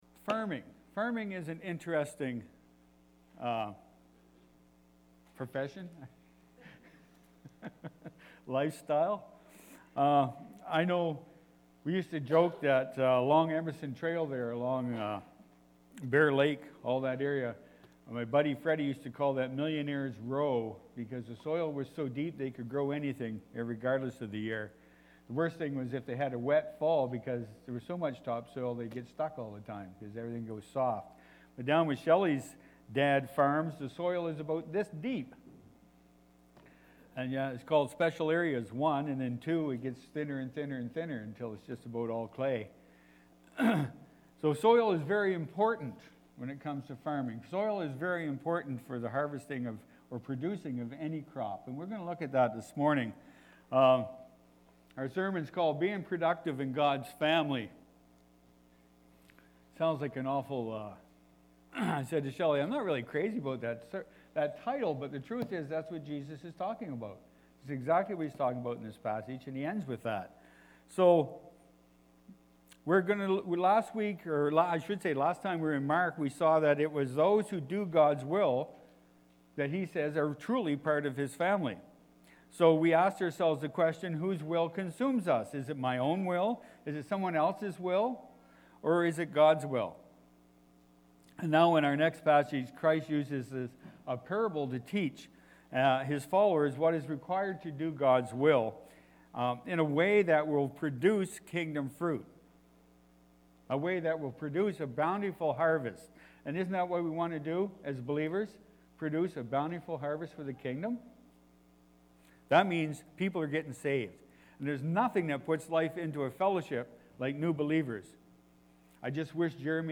Jan-7-2024-sermon-audio.mp3